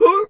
seal_walrus_2_hurt_02.wav